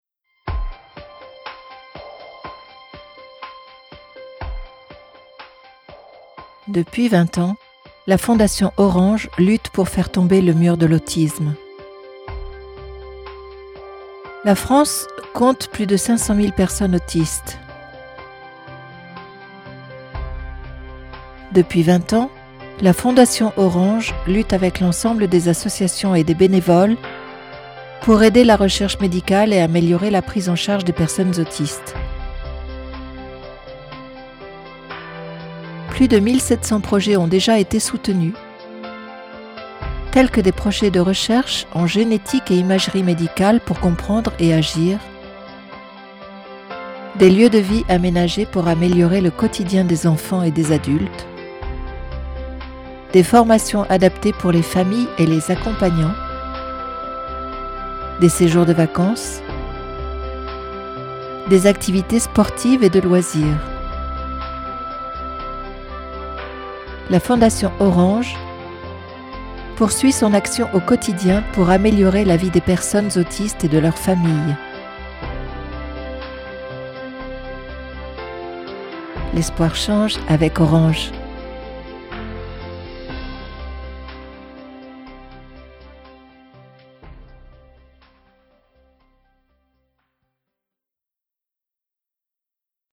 Voix off, comédienne française, voix femme adulte, medium, documentaire, institutionnel, audio guide, elearning
Sprechprobe: Werbung (Muttersprache):